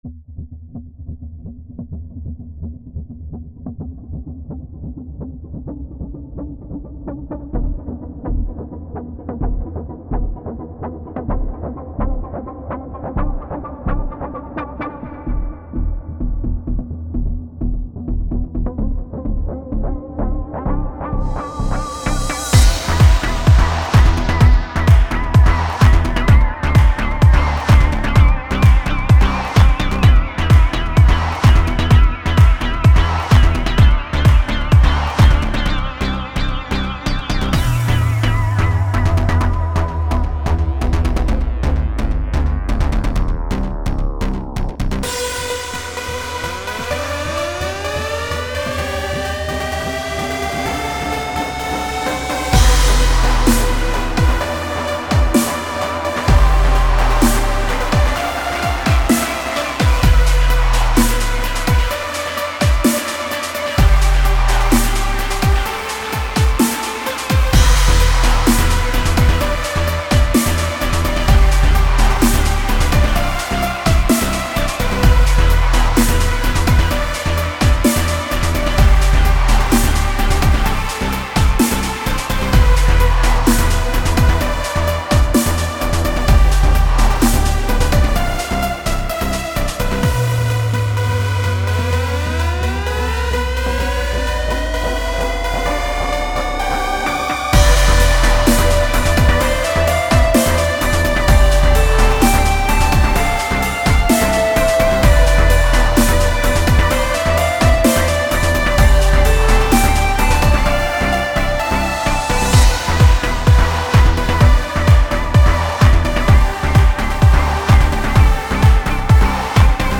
It's different for me to make harder hitting stuff with more grungy dubstep tones.
It's different for me to make harder hitting stuff with more grungy dubstep tones I hope I did this one well kind of a progressive house into new age dubstep grunge.